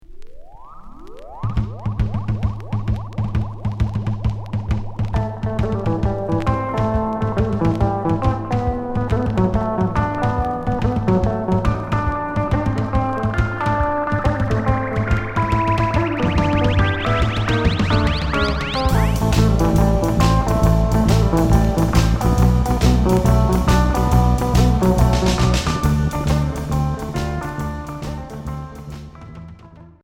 Rock blues